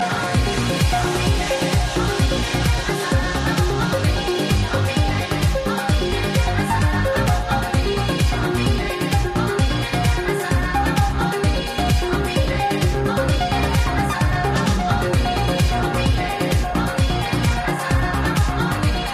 anybody knows the trackname of this accapellas?